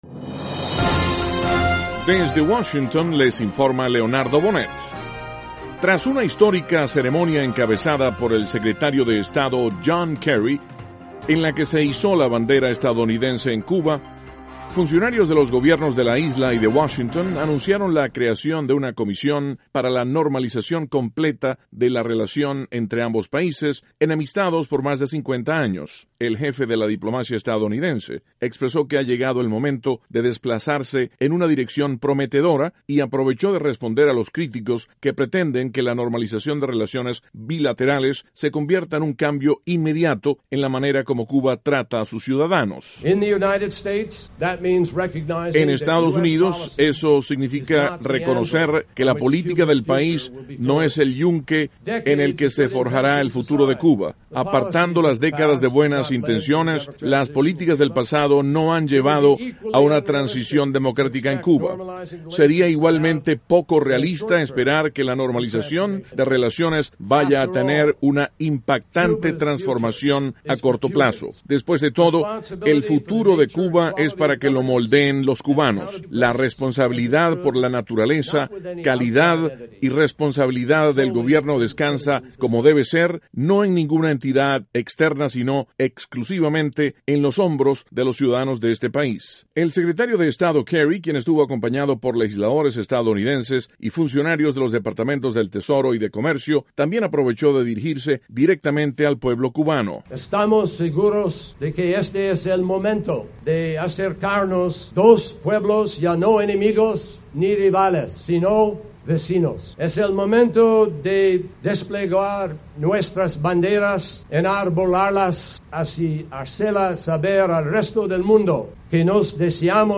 Estados Unidos enarbola su bandera en La Habana. Sonidos de: John Kerry (Inglés y español), Canciller cubano Bruno Rodriguez Parrilla (2) y el senador Marco Rubio (1).